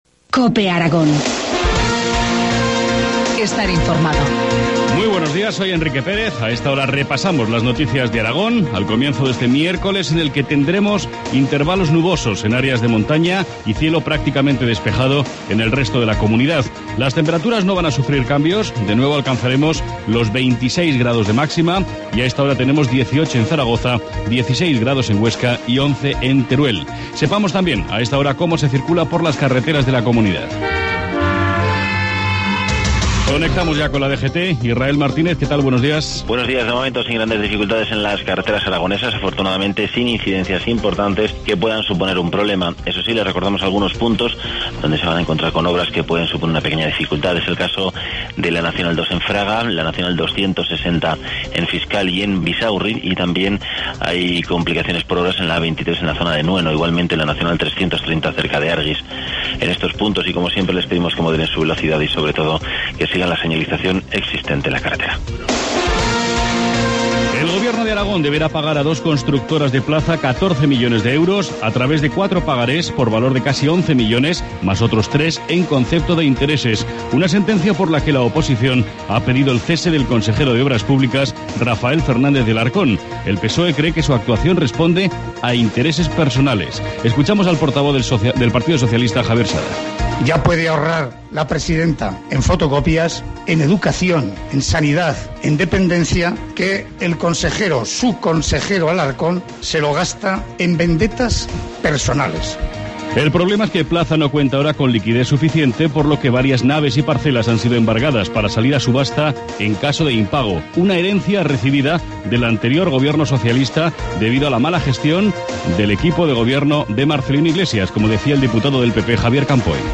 Informativo matinal, miércoles 8 de mayo, 7.25 horas